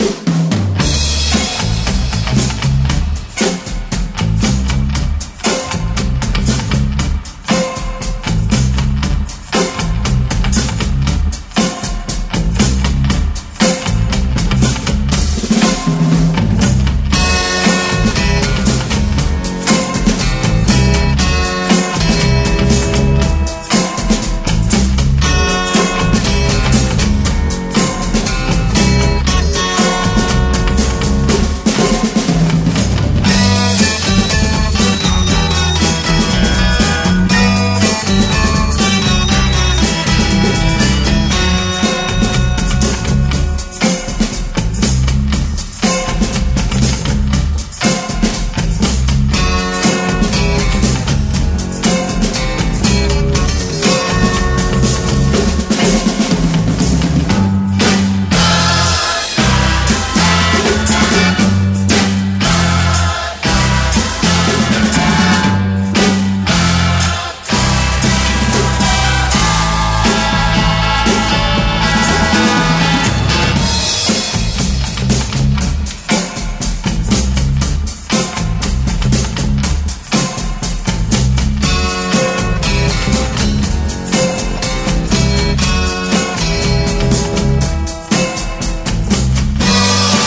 マイナーなファンク・チューンを多数収録した、架空のアクション映画サントラとして作られたアルバム。